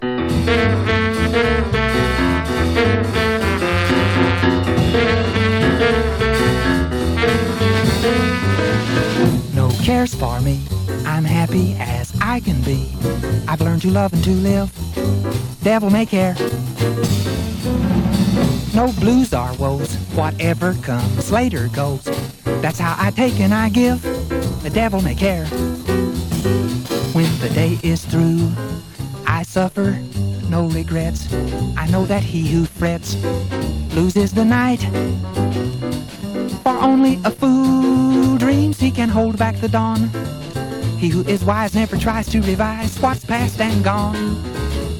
Jazz, Pop, Vocal　USA　12inchレコード　33rpm　Stereo